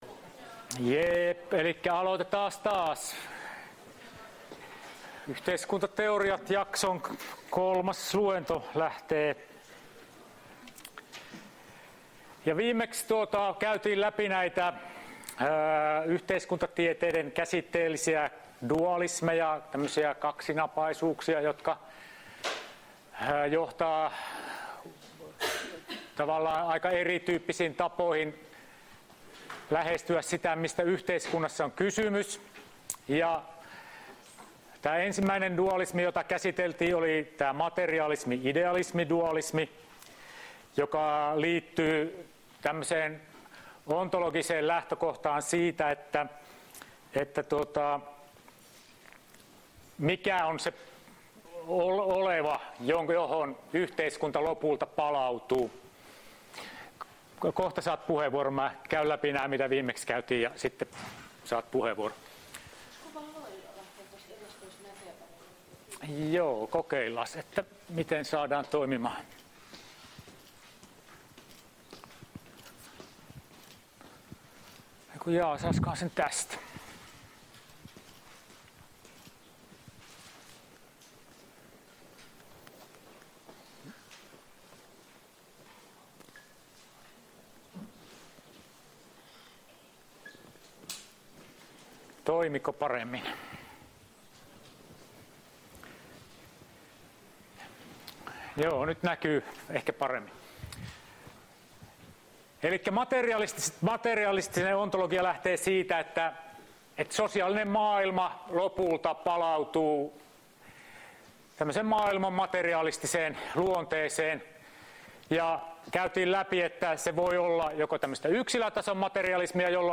Luento 05.11.2018